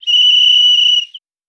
Whistle Long Blow.wav